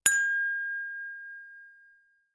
Huawei Bildirim Sesleri